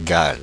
Ääntäminen
Paris: IPA: [gal]